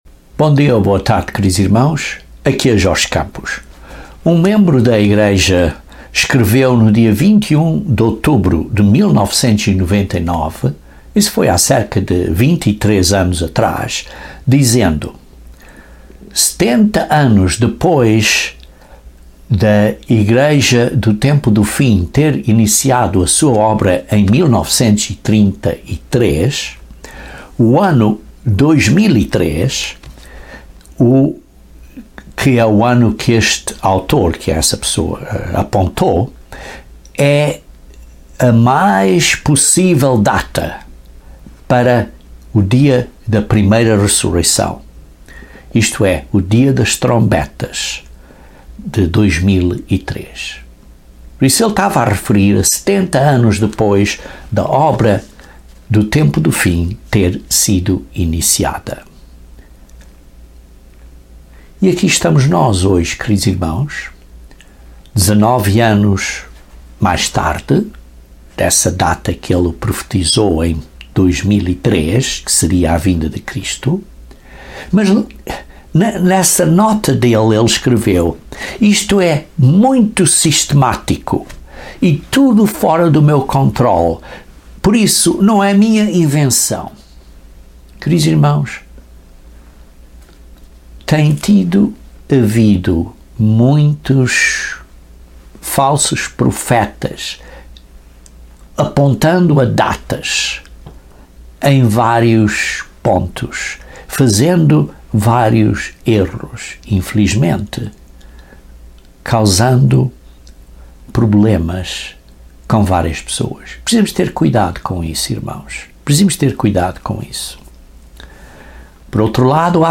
Este sermão decreve como o dia das Trombetas é o dia Santo pivotal, crítico e decisivo nesta Boa Nova. É quando o mistério de Deus é revelado e quando Deus atravéz de Jesus Cristo toma posse dos reinos deste mundo.